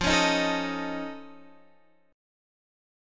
Listen to F+7 strummed